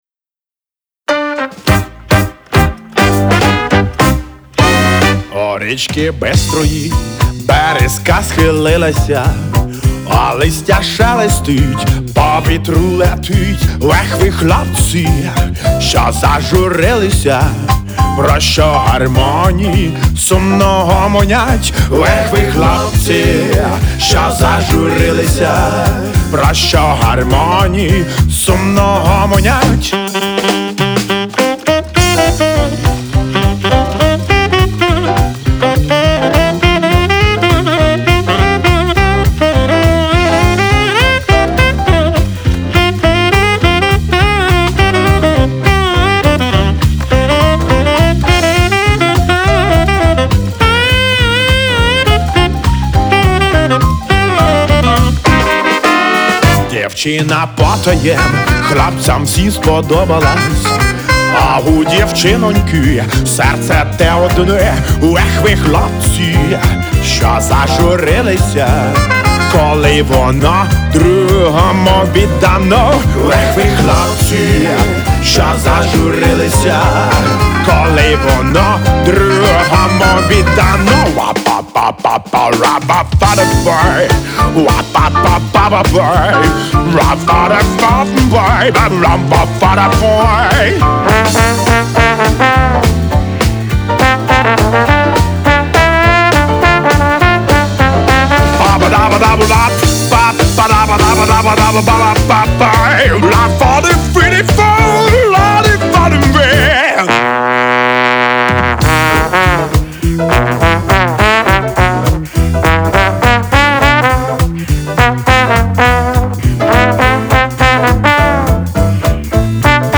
Стиль : retro pop